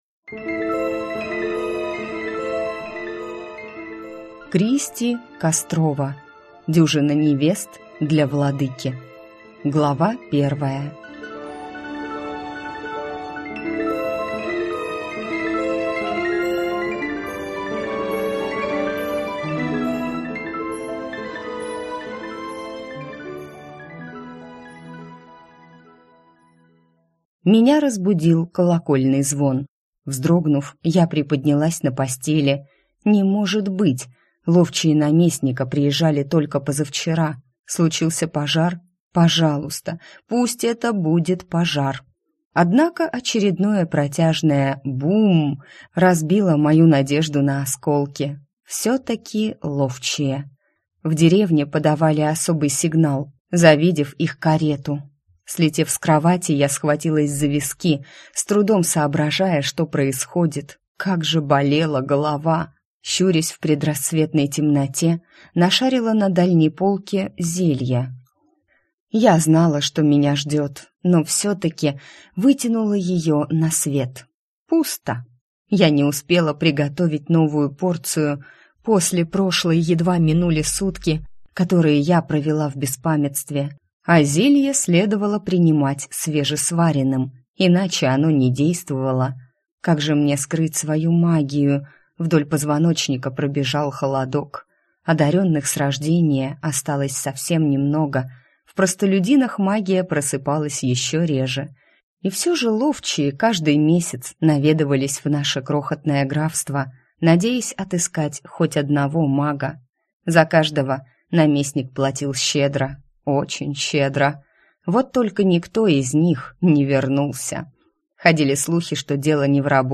Аудиокнига Дюжина невест для Владыки | Библиотека аудиокниг